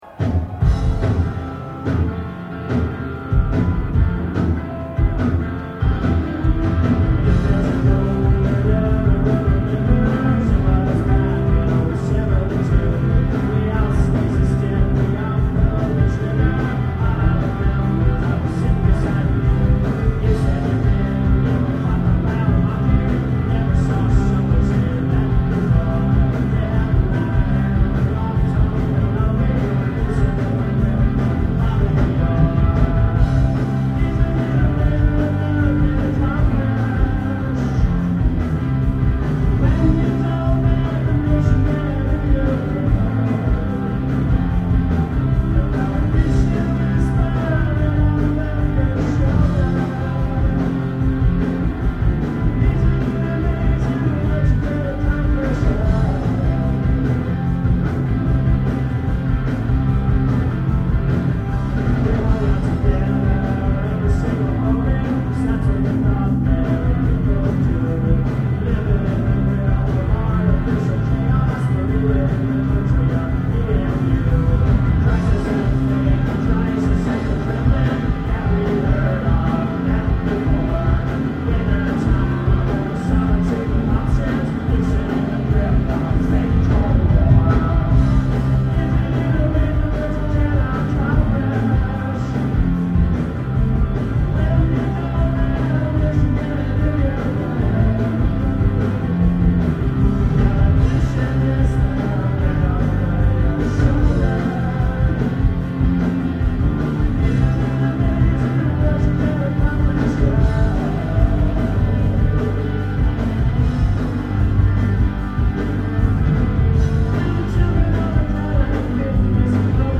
Live In 1997